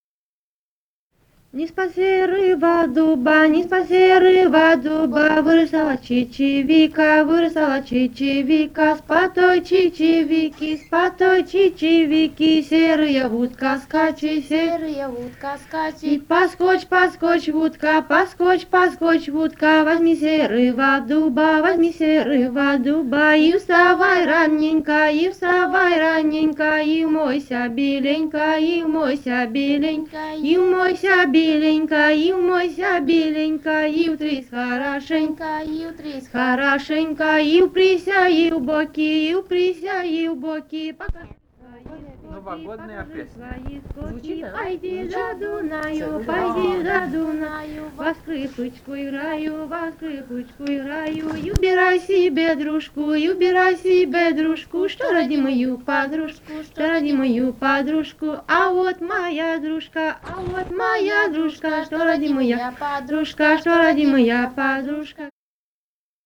полевые материалы
«Ни с-под серого дуба» (плясовая на масленицу).
Румыния, с. Переправа, 1967 г. И0974-06